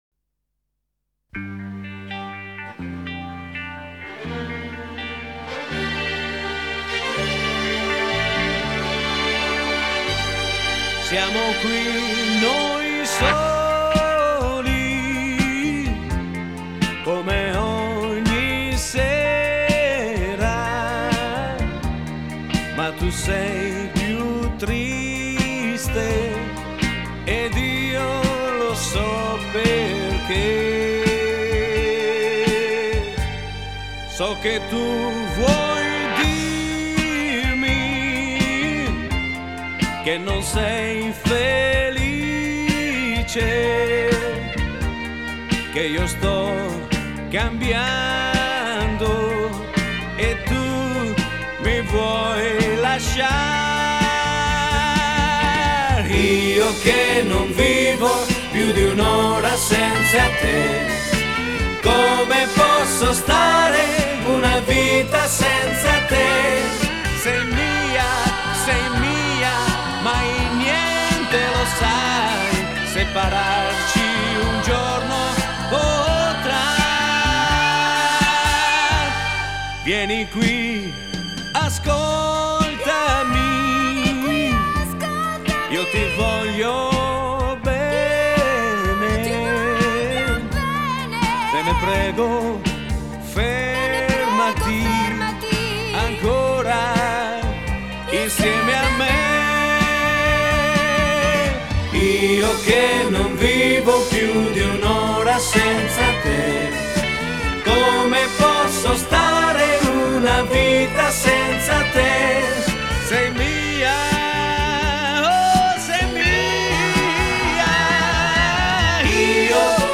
Genre: Retro Pop